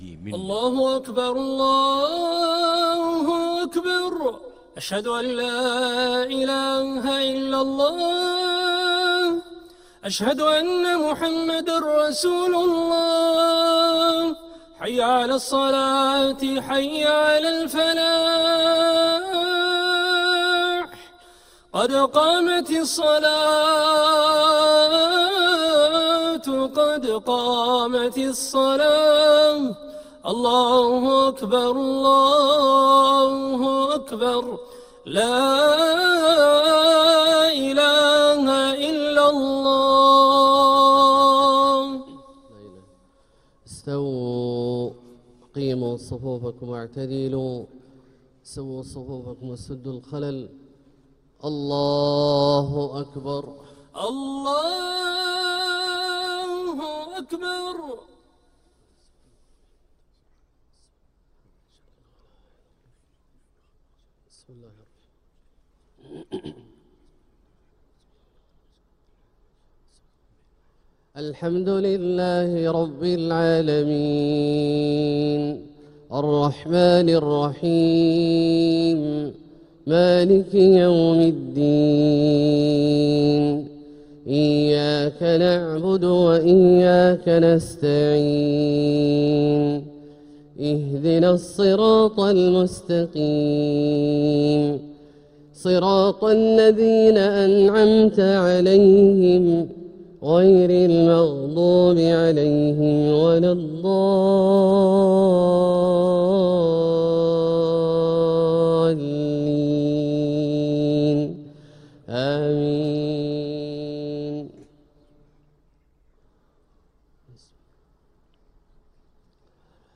Makkah Fajr (Surah Ta-Ha 105-135) Sheikh Juhany Download 128kbps Audio